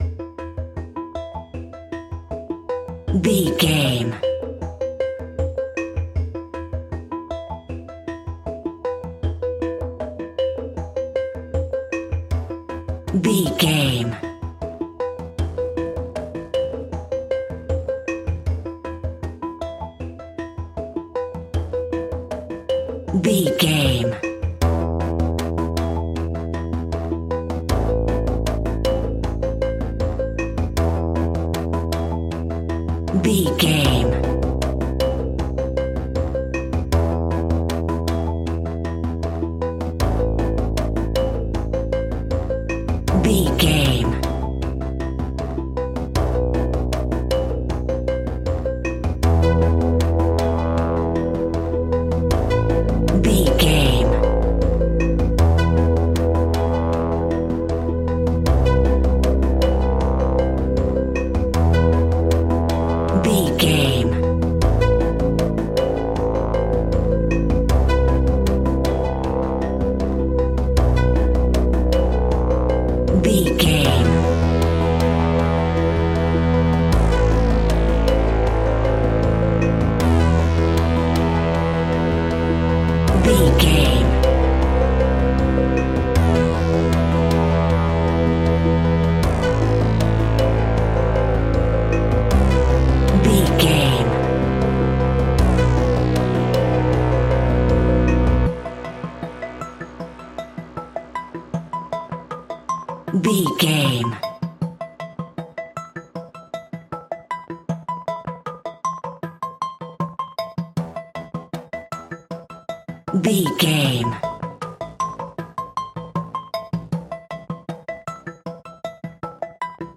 In-crescendo
Thriller
Aeolian/Minor
ominous
dark
haunting
eerie
synthesizer
electronic music
electronic instrumentals
Horror Synths